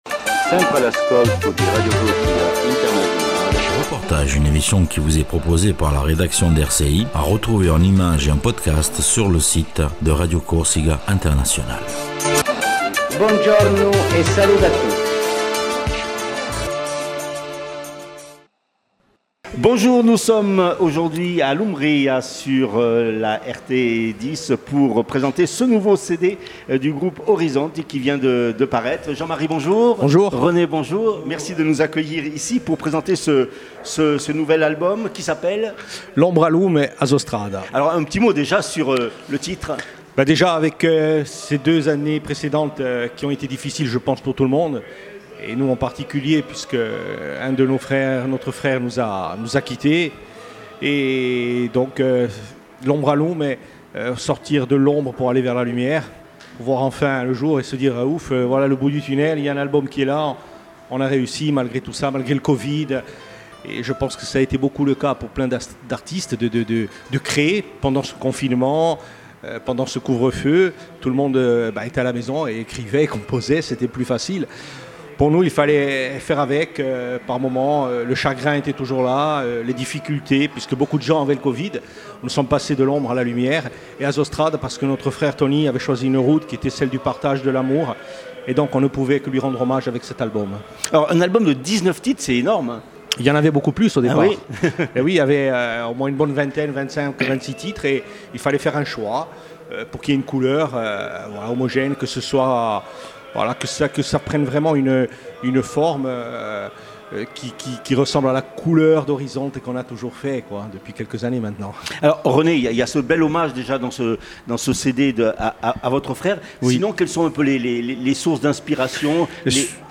REPORTAGE SUR LE GROUPE ORIZONTE